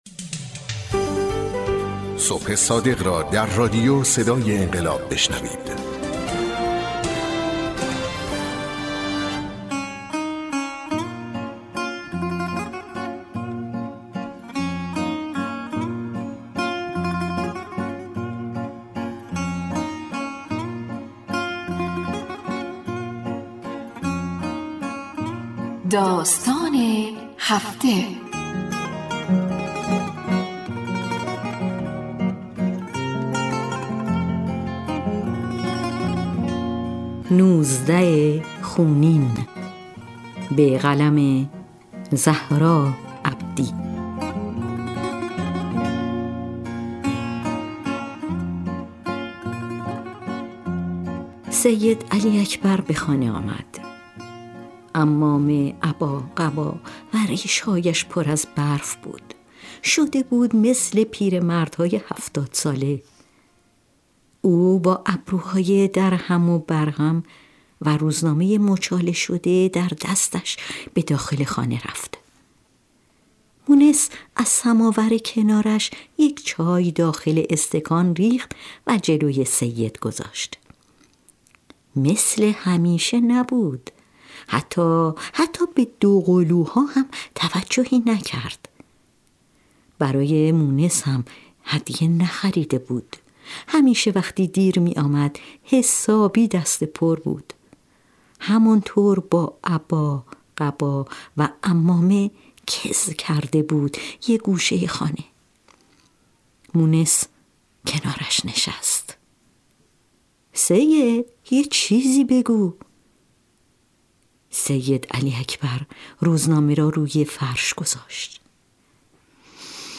برچسب ها: بصیرت ، صدای انقلاب ، نوزده دی ، داستان